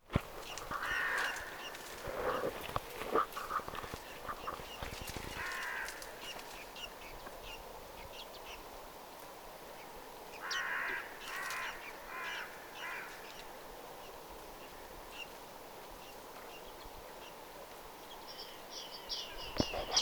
viiksitimalien lähiääntelyä, 1
viiksitimalien_lahiaantelya.mp3